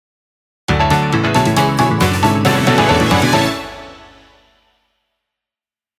みじかめサウンドなシリーズです
ゲームサウンドやアイキャッチを意識した